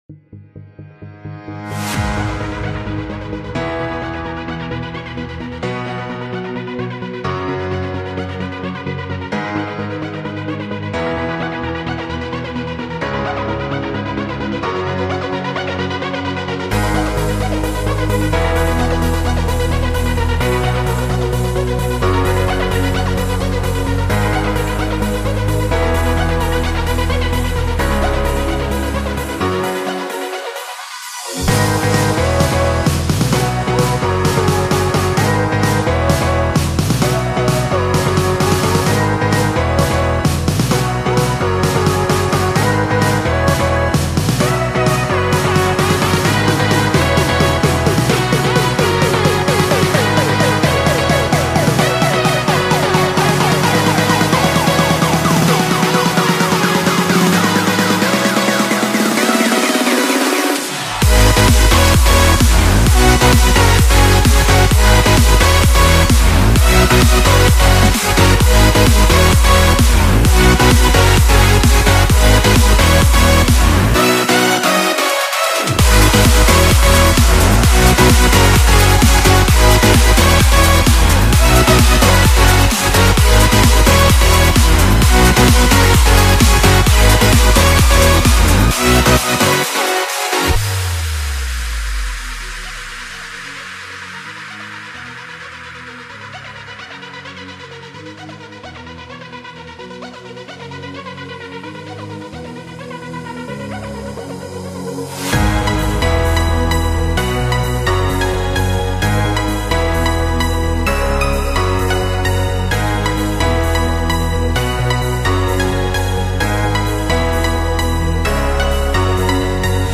Euphoric, Energetic, Happy, Hopeful